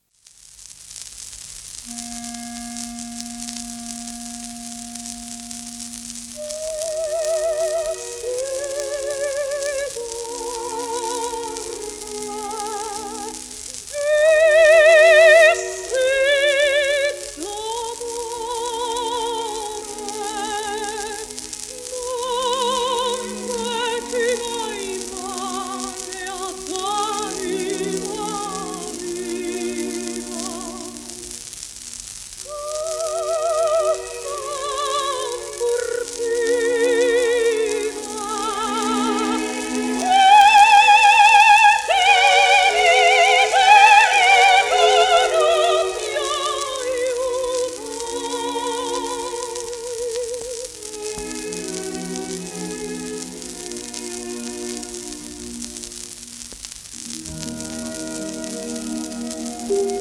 1951年頃の録音、デッカカーブ[ffrr]表記